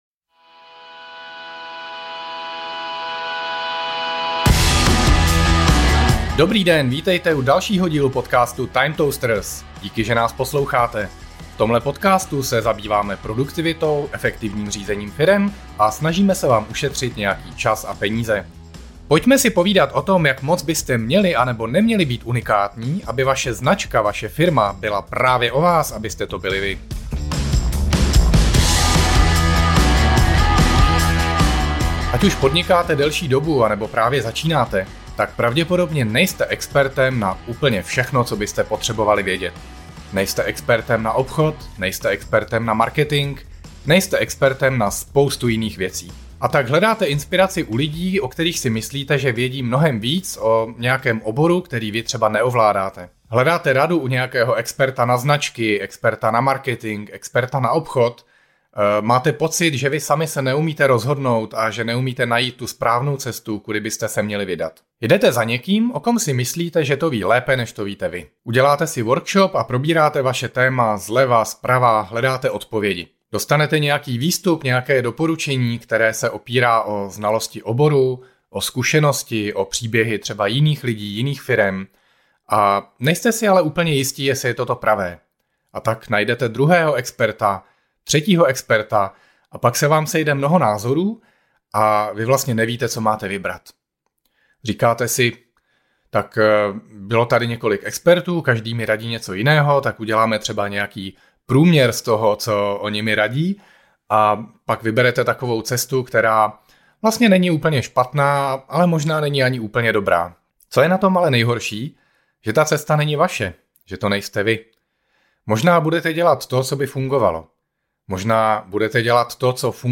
To se dozvíte v této sólo epizodě.